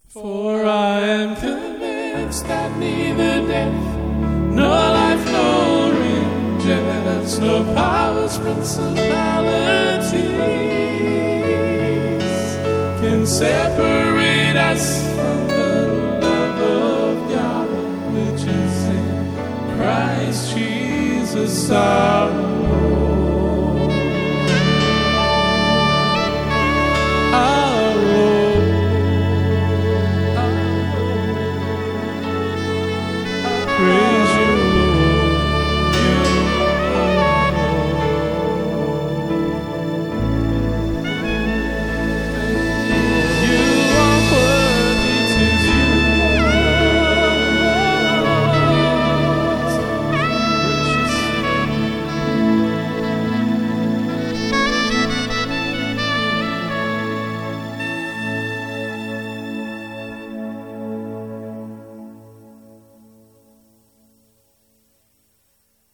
Soprano sax